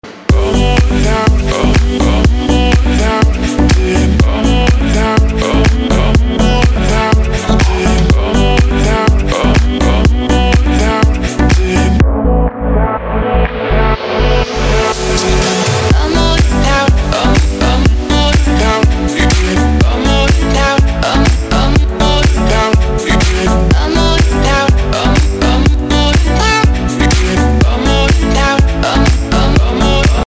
dance
Electronic
house